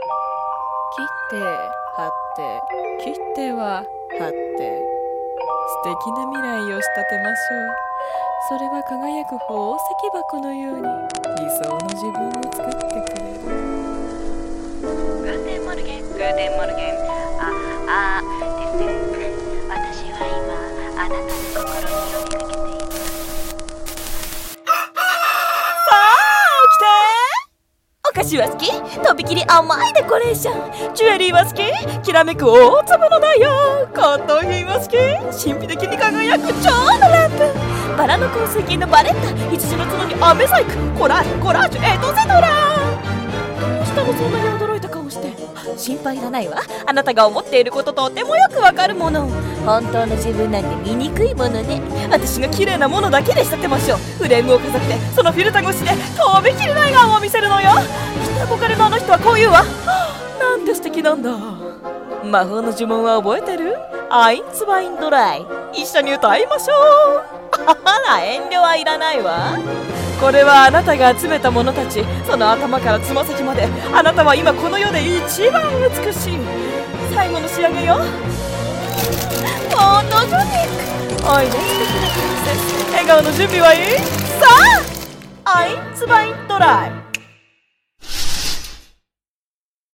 CM風声劇